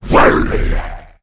c_saurok_atk2.wav